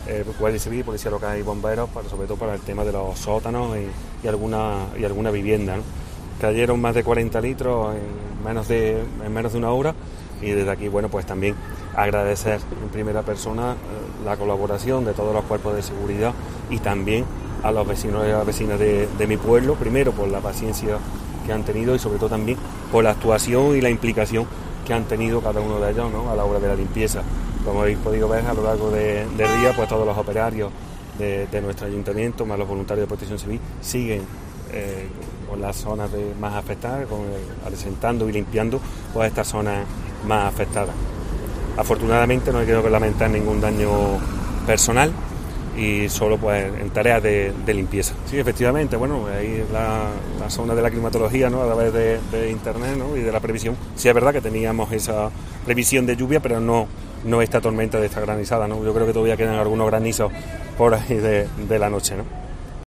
Escucha al alcalde de La Carlota, Antonio Granados